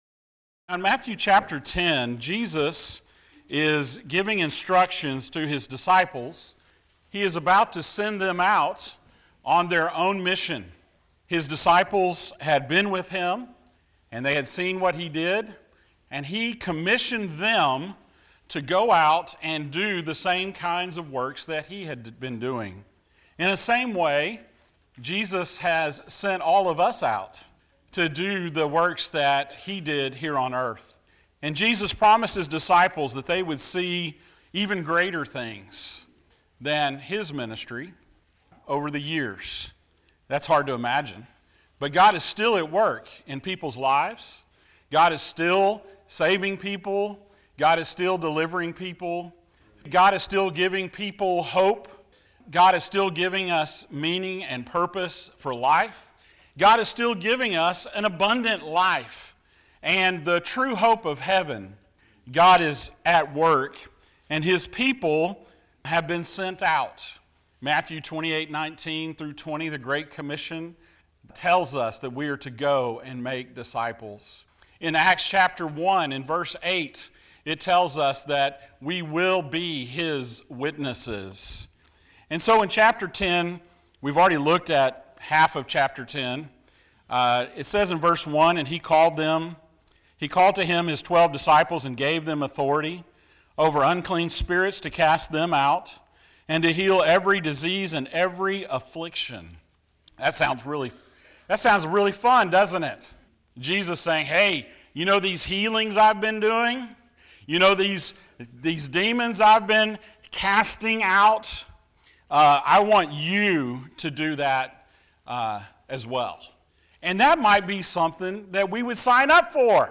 Passage: Matthew 10:11-25 Service Type: Sunday Morning